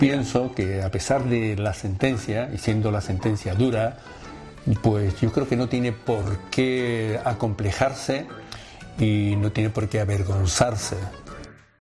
Declaraciones de Manuel Chaves